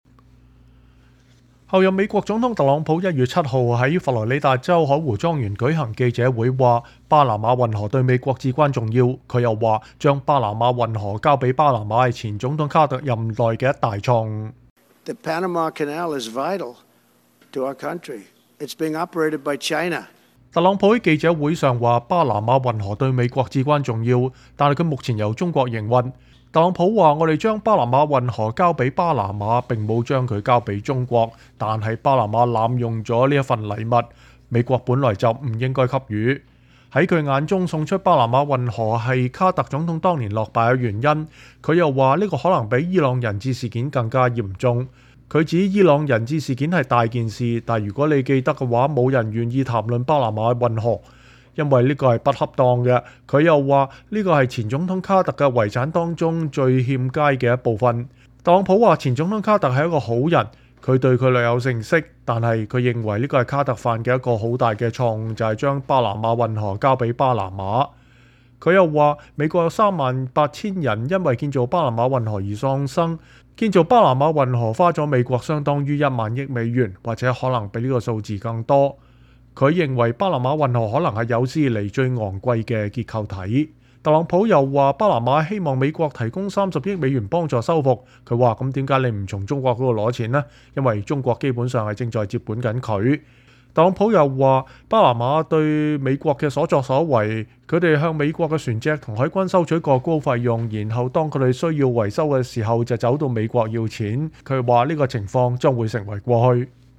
美國候任總統特朗普1月7日在佛羅里達州海湖莊園舉行的記者會中說，“巴拿馬運河對美國至關重要”。他又說，把巴拿馬運河交給巴拿馬是前總統卡特任內的一大錯誤。